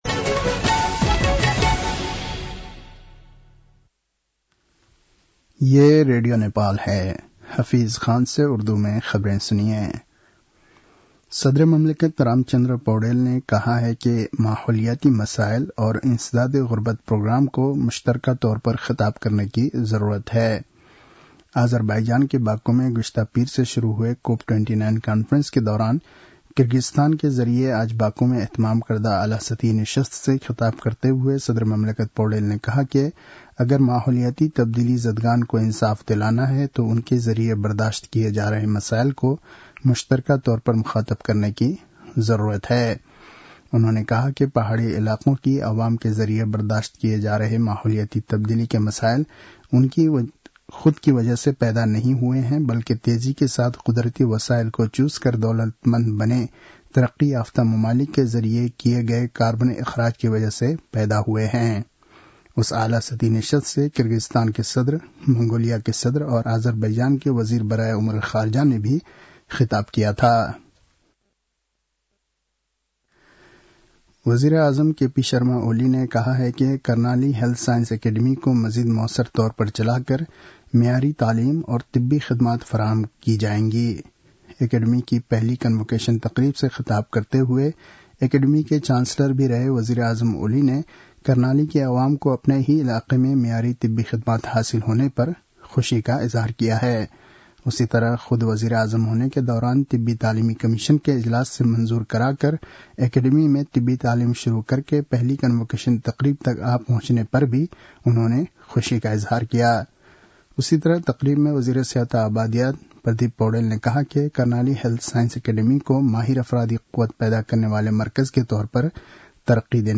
उर्दु भाषामा समाचार : २९ कार्तिक , २०८१
Urdu-news-7-28.mp3